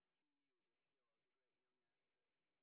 sp02_train_snr10.wav